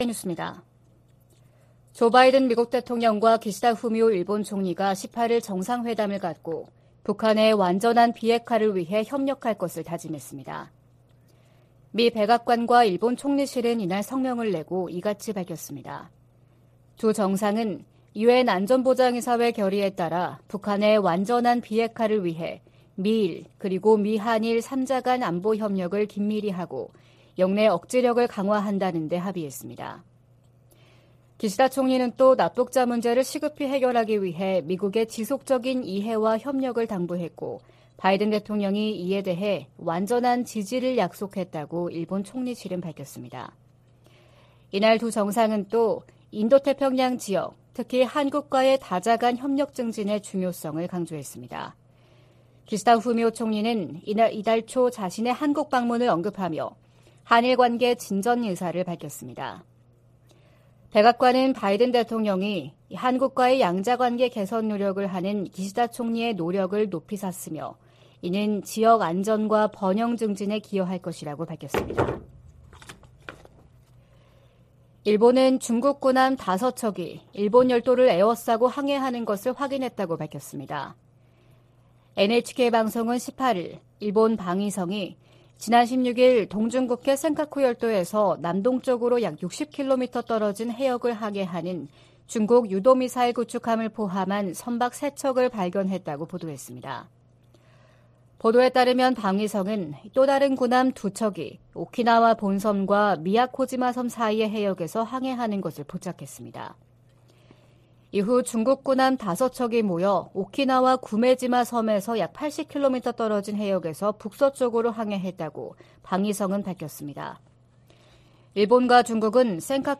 VOA 한국어 '출발 뉴스 쇼', 2023년 5월 19일 방송입니다. 일본 히로시마에서 열리는 주요 7개국(G7) 정상회의 기간에 미한일 정상회담을 추진 중이라고 미국 백악관 고위 당국자가 밝혔습니다.